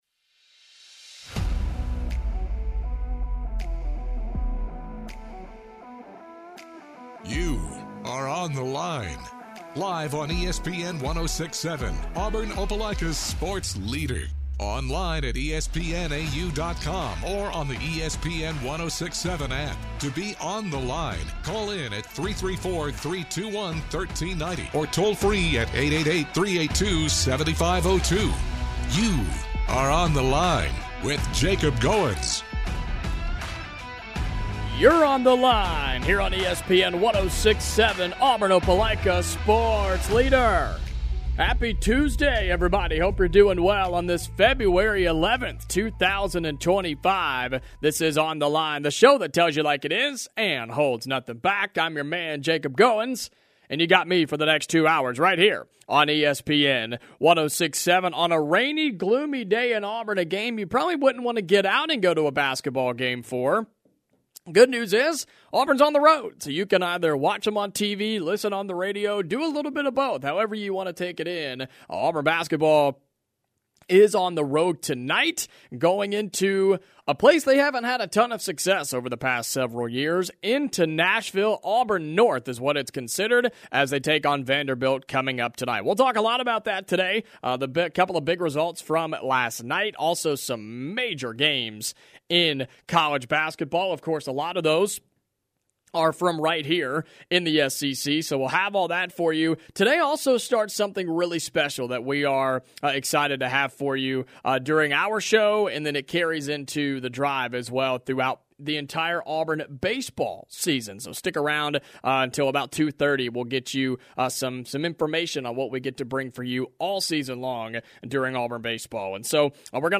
Then the guys debate on who has more pressure to win tonight and this weekend: Auburn or Alabama. They then make their picks on the biggest games around the SEC.